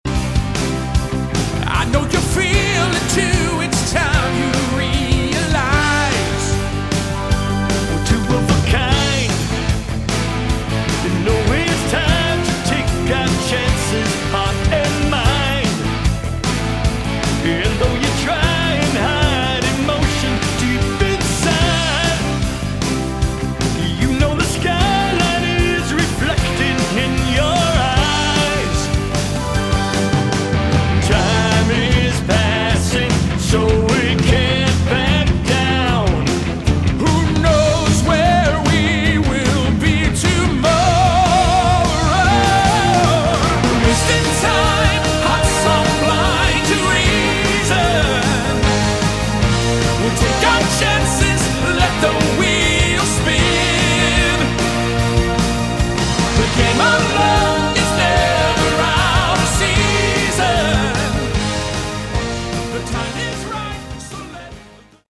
Category: AOR
drums, percussion
keyboards, orchestration